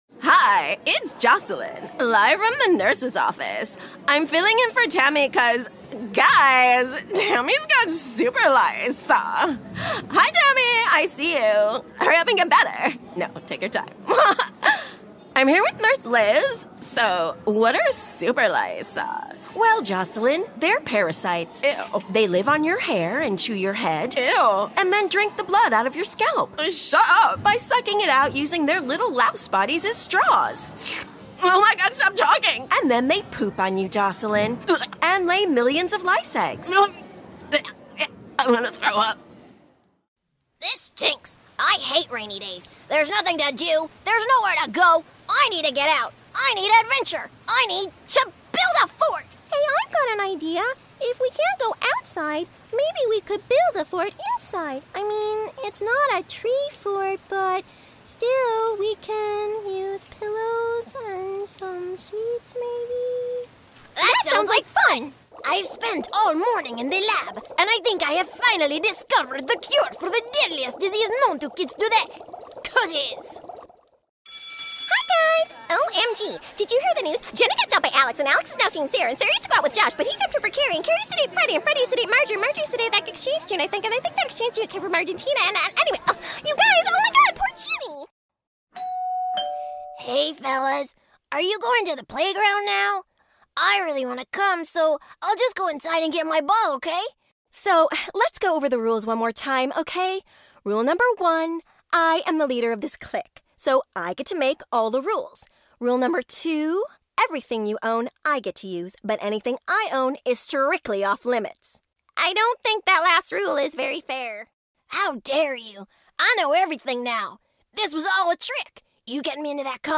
Animation Reel
Playing age: Teens - 20s, 20 - 30sNative Accent: AmericanOther Accents: American, RP
• Native Accent: American-Midwest
Her refined accent skills, honed through work in LA, Ireland, and the UK, lend authenticity and depth to every performance, making her a unique, compelling and dynamic voice actor.